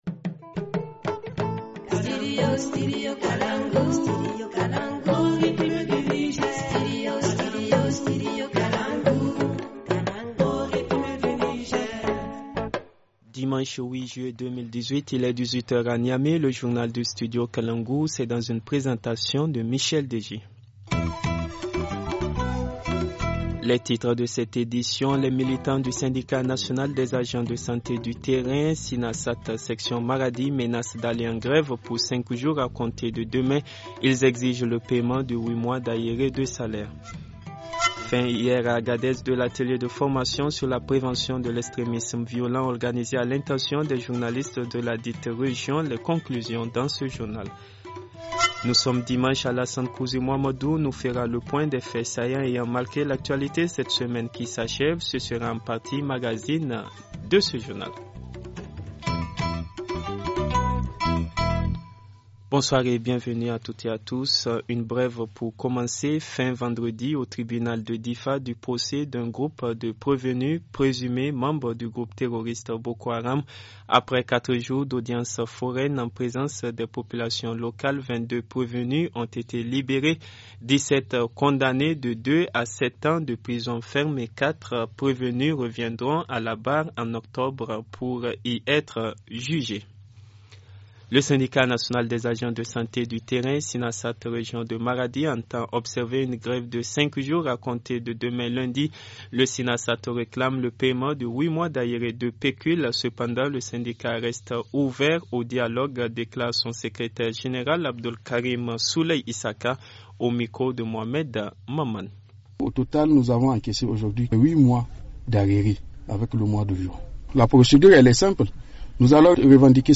Le journal du 08 juillet 2018 - Studio Kalangou - Au rythme du Niger